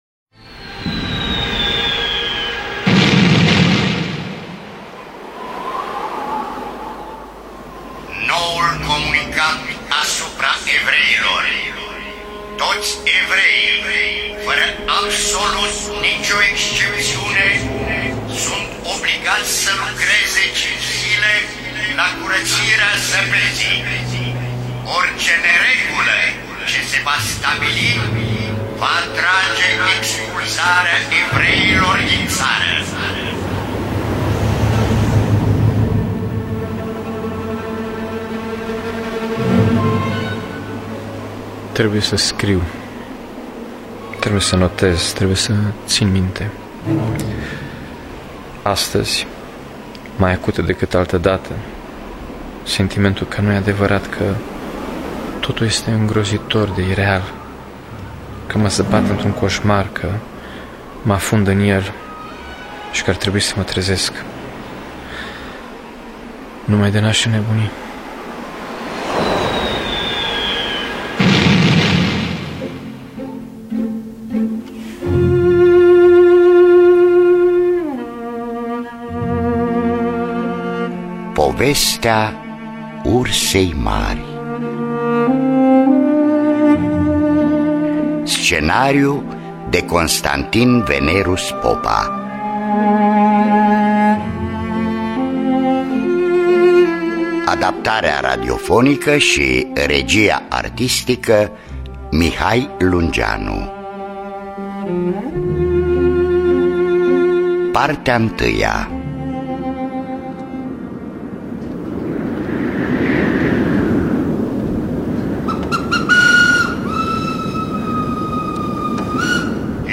Adaptarea radiofonicã